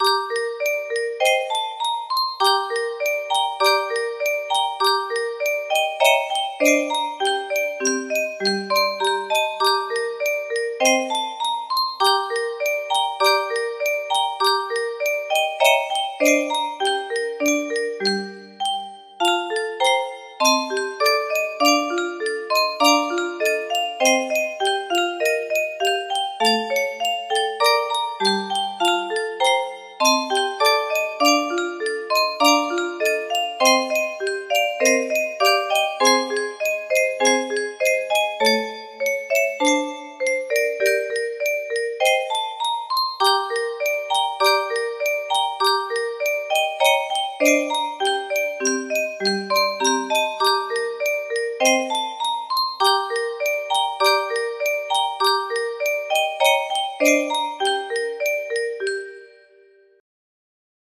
Clone of (30) Tchaikovsky - Swan Lake music box melody